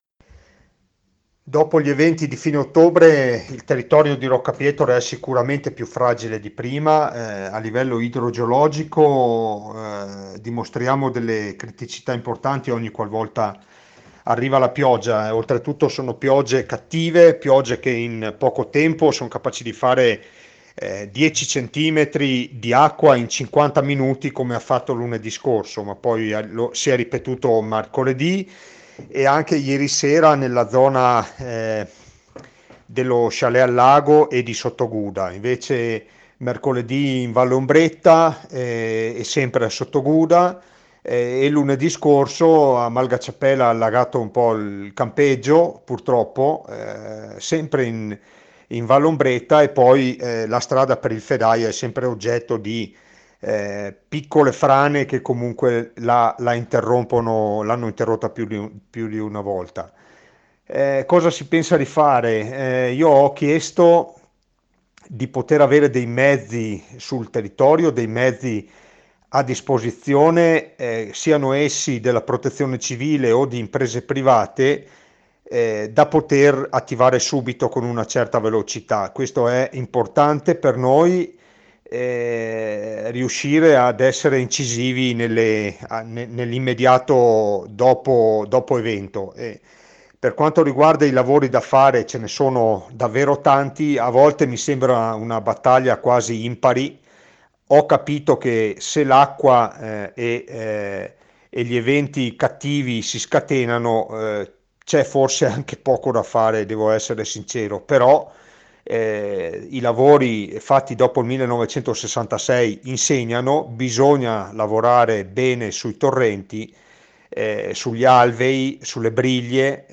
IL SINDACO DI ROCCA PIETORE, ANDREA DE BERNADIN